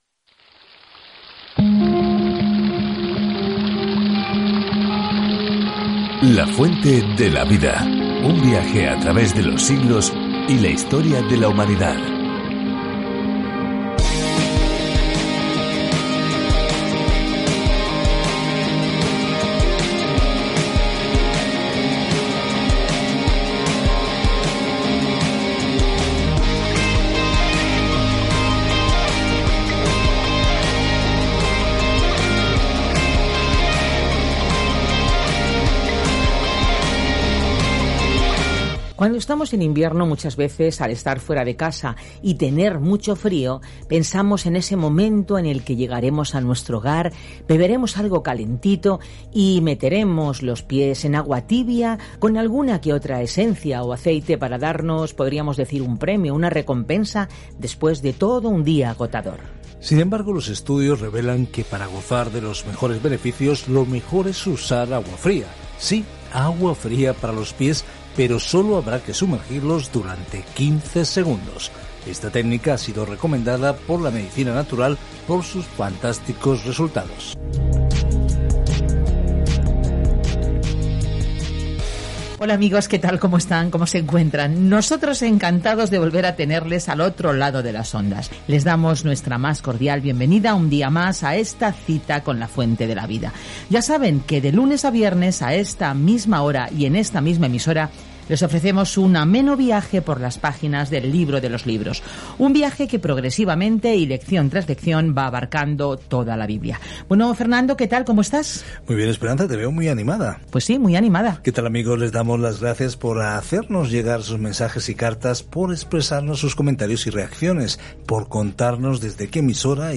Escritura NAHUM 1:3-10 Día 2 Iniciar plan Día 4 Acerca de este Plan Nahum, cuyo nombre significa consuelo, trae un mensaje de juicio a los enemigos de Dios y trae justicia y esperanza a Israel. Viaje diariamente a través de Nahum mientras escucha el estudio de audio y lee versículos seleccionados de la palabra de Dios.